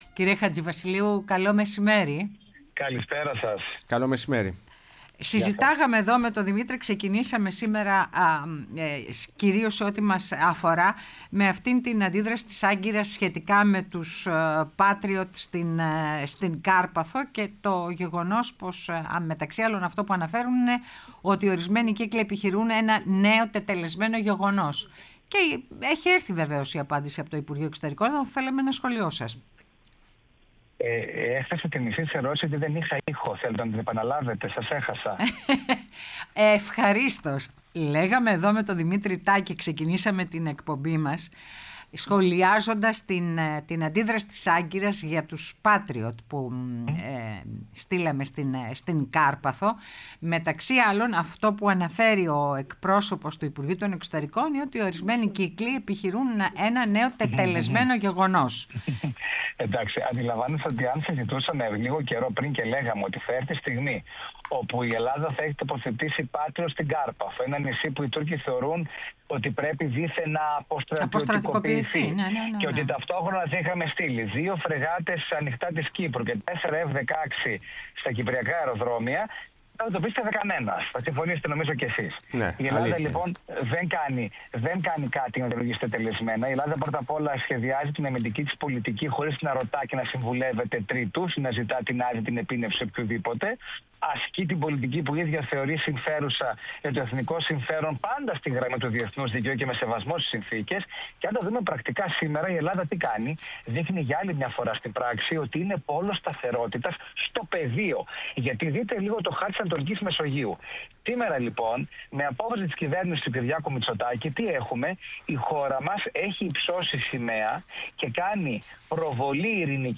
Ο Τάσος Χατζηβασιλείου, διεθνολόγος και βουλευτής ΝΔ, μίλησε στην εκπομπή Ναι μεν αλλά